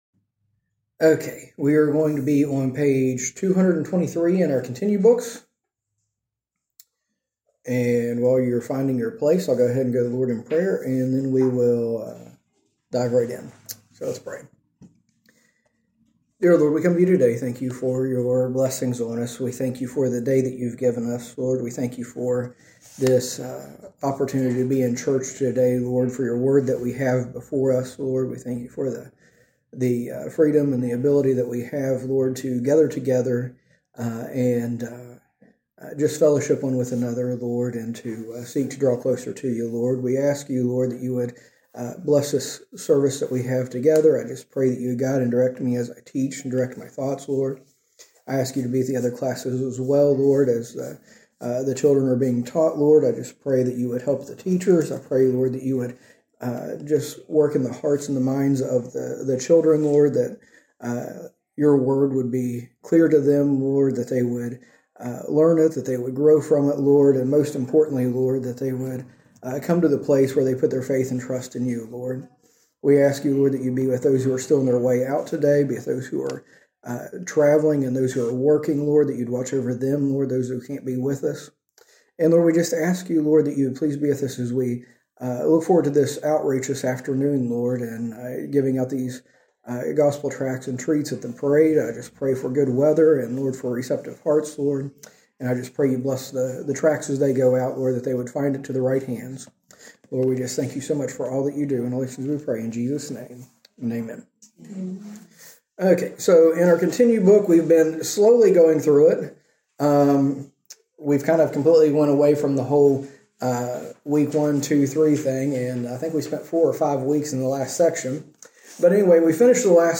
A message from the series "Continue."